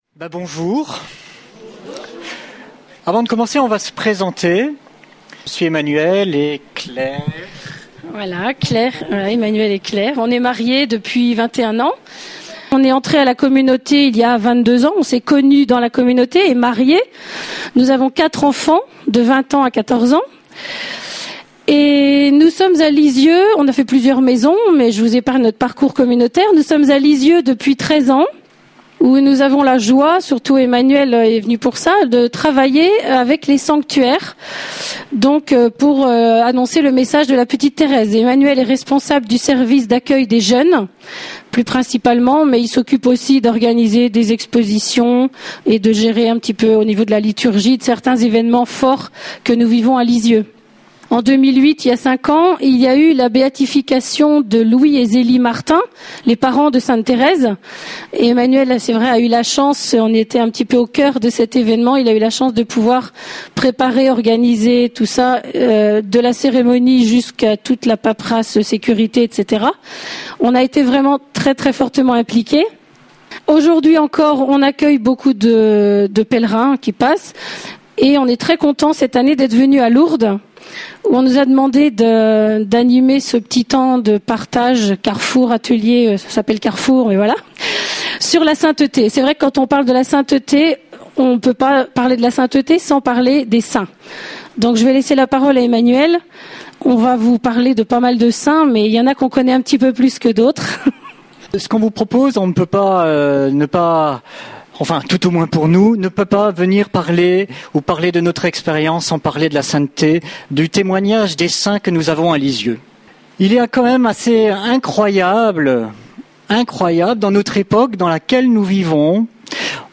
(Session B�atitudes Lourdes)